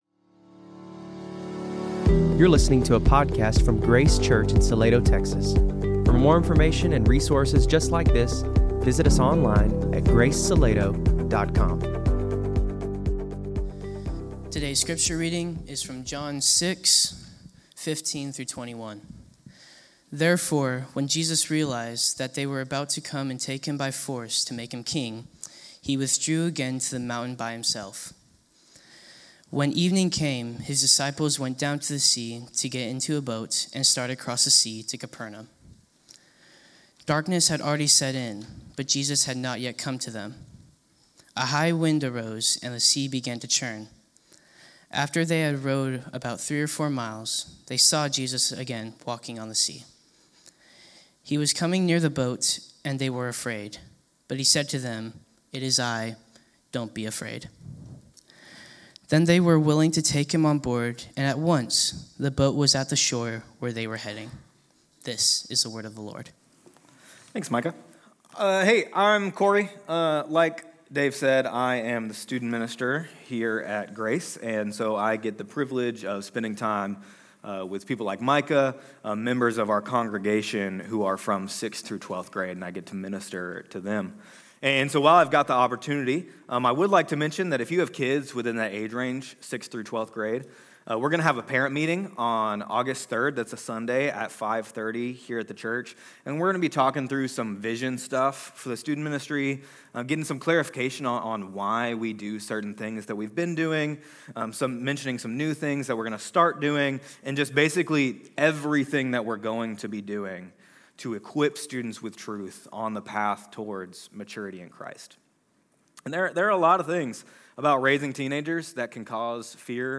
Sermons & devotionals from Grace Church Salado, located in Salado Texas.